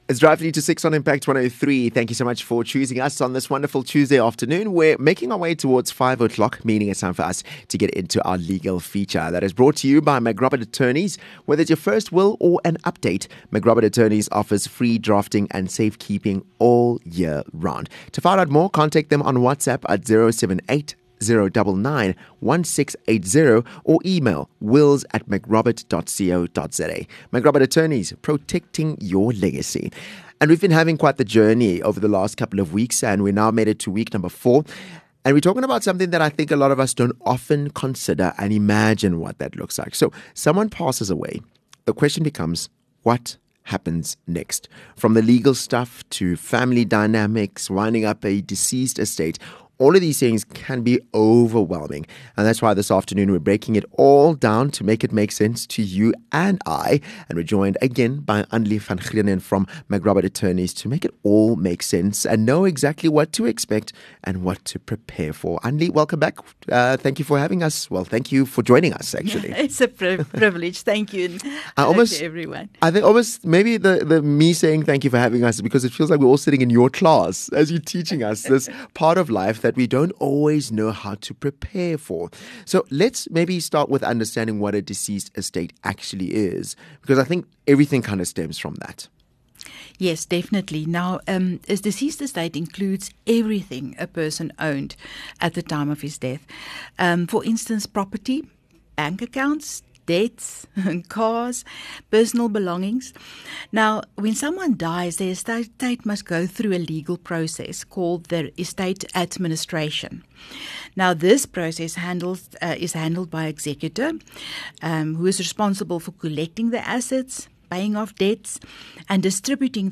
Interview 4